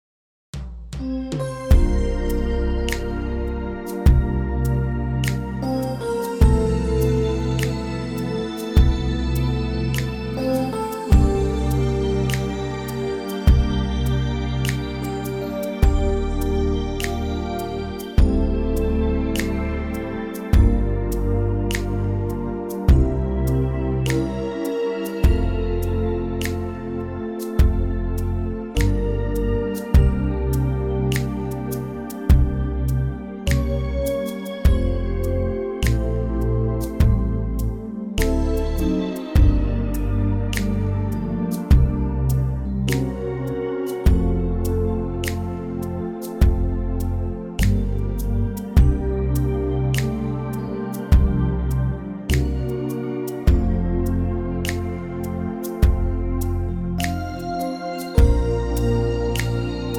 key - F - vocal range - C to E